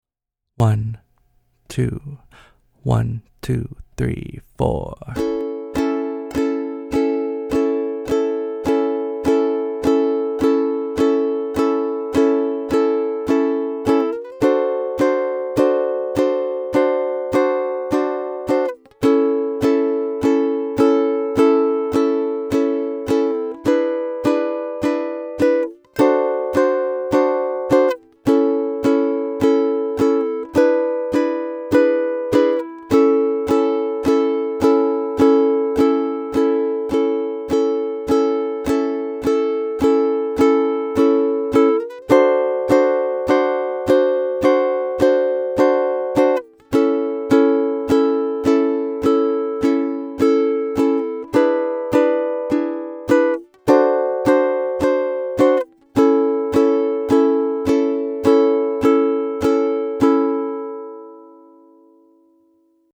Backing Track: 1-6-2-5 Progression - Key of C